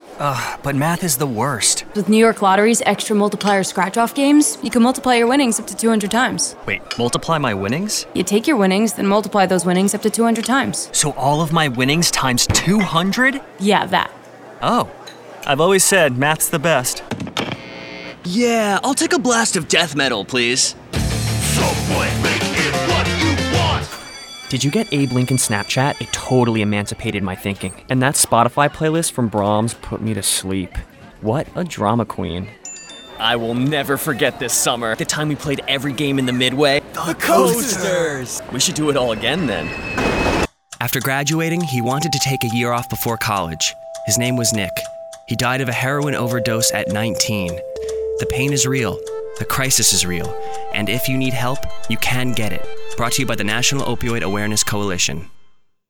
Animation Demo